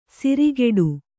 ♪ sirigeḍu